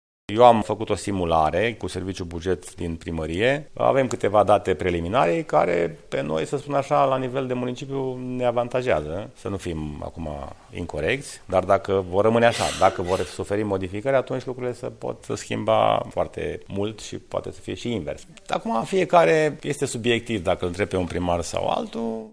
Primarul Brașovului, George Scripcaru: